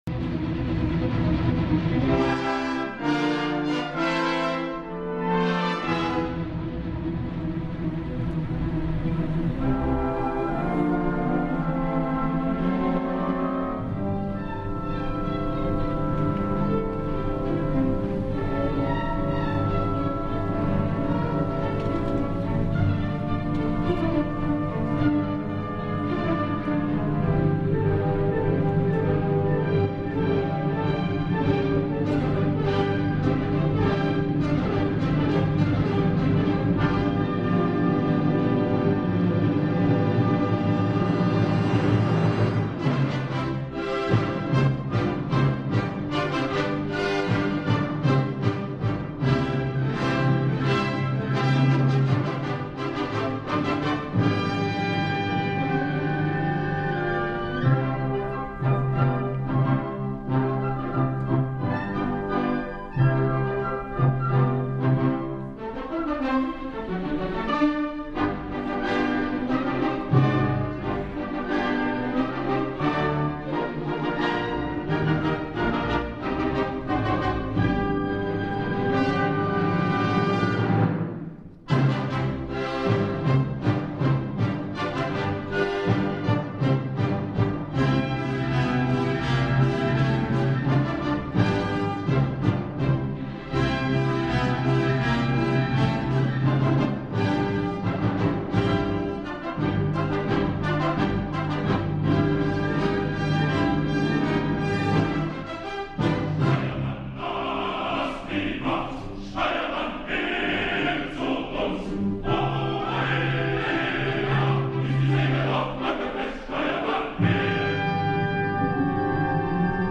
Cor, orquestra del Festival de Bayreuth, solistes i Sebastian Weigle en Die Fliegende Holländer al Liceu.
El record perdurarà en la memòria col·lectiva de tots aquells que varem tenir la sort d’assistir-hi i aquí us deixo tres moments que he cregut especialment emocionants: Comencem en l’ordre cronològic per Der Fliegende Holländer i com que el que ens va impactar més en totes les òperes va ser l’excel·lència del Cor i l’Orquestra del Festival, escoltem l’escena del Cor de mariners del tercer acte. Dirigeix l’orquestra i el Cor Sebastian Weigle i el timoner que escoltarem és el tenor Benjamin Bruns. La gravació és del dia 4 de setembre , per tant del segon dia .
cor-de-mariners-acte-3er.mp3